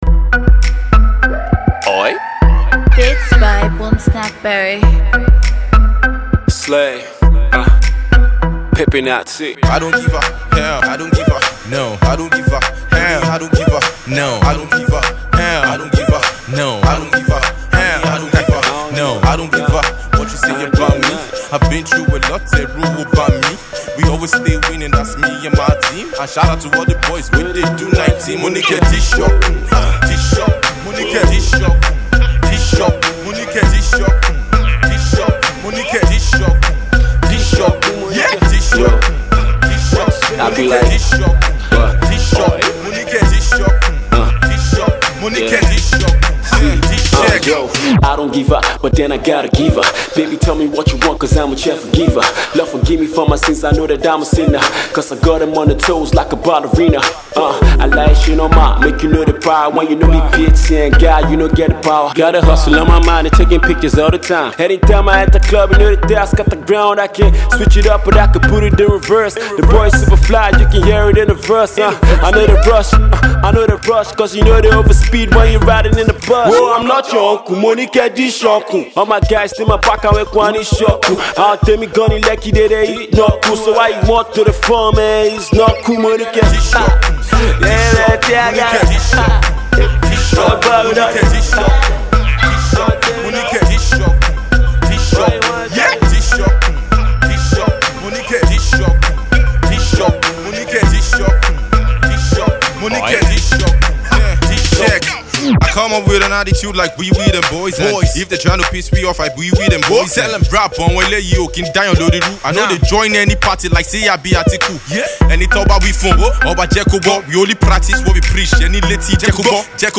Alternative Rap
finely crafted rap song